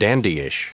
Prononciation du mot dandyish en anglais (fichier audio)
Prononciation du mot : dandyish